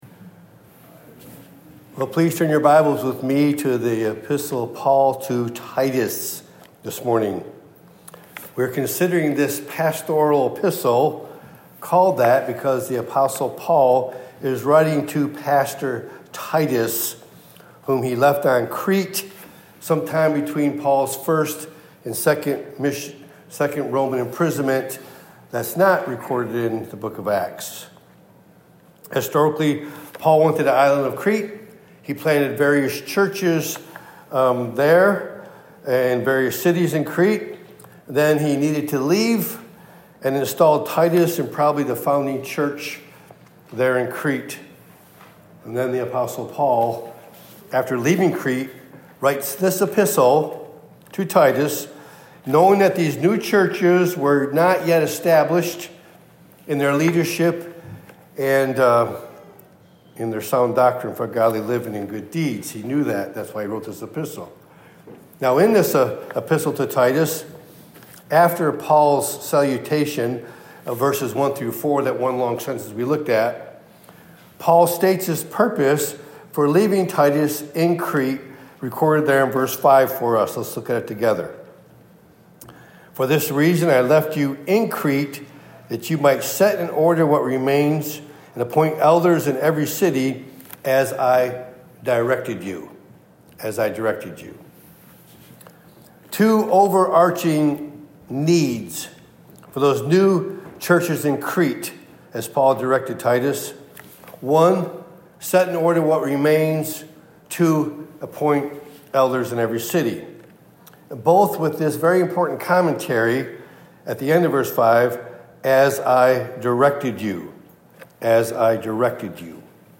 Topic: Sunday Morning